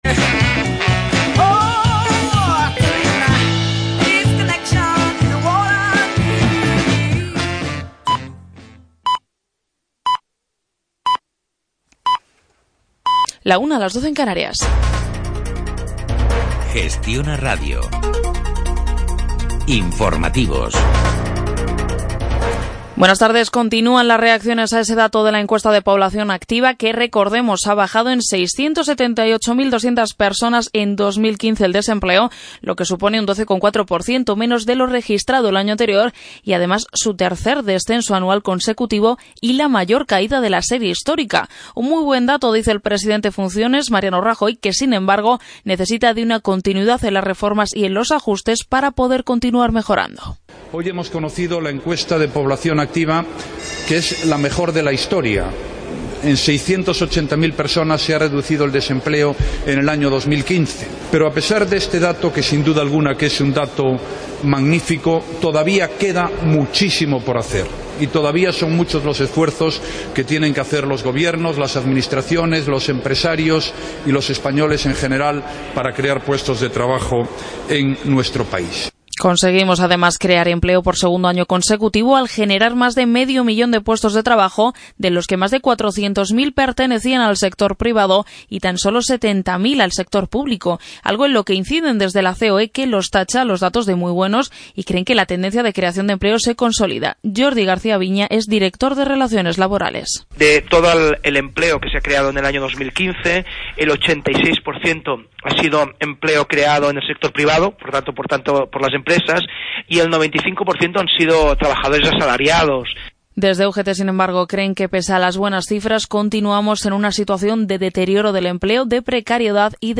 El programa de radio ‘Enfermedades Raras’ del 28 de enero de 2015